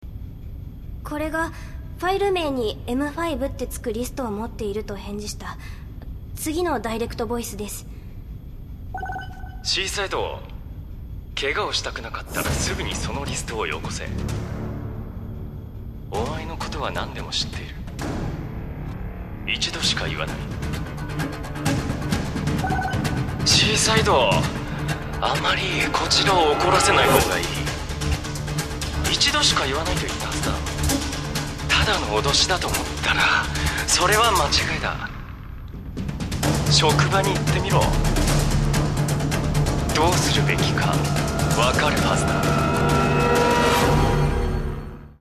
[オーディオブック] 私を見て（サイバー犯罪防止第6課）